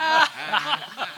laughter 02.aiff